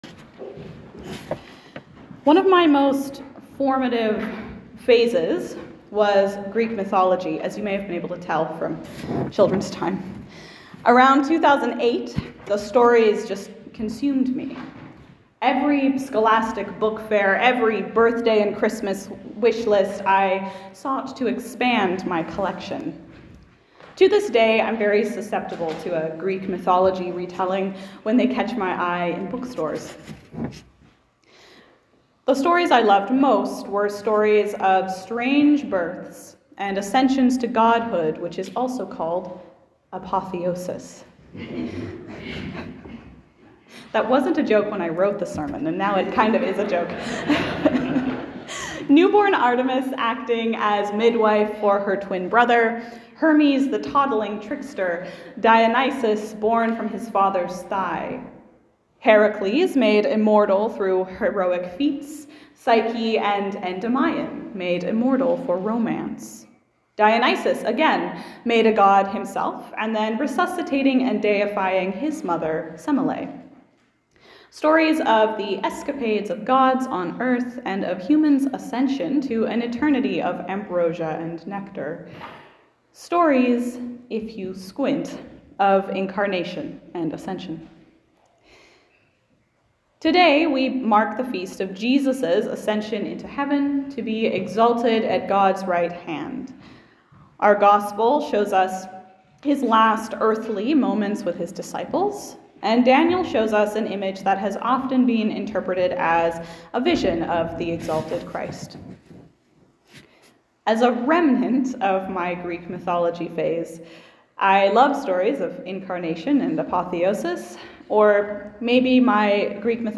Sermon 1 June